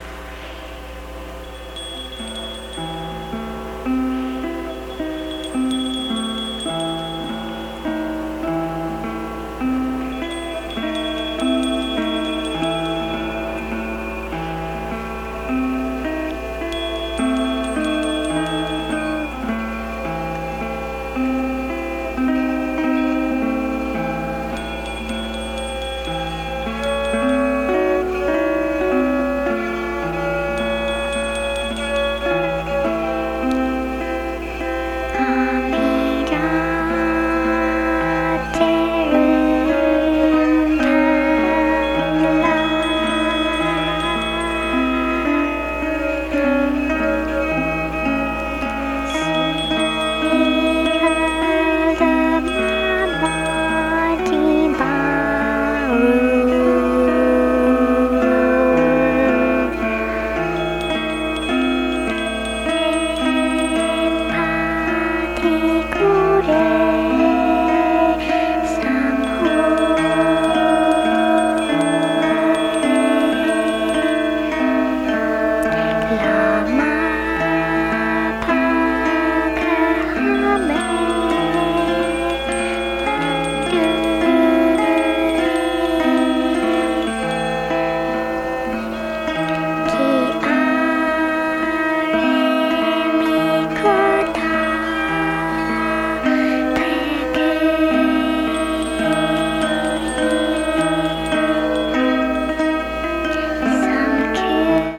幻想的で牧歌的な世界に、ホラーを交えて表現したような傑作集！
ディスク２ではテープ編集、エレクトロニクスを取り入れ、作風が刻々と進化して行く現在までの状況がわかる。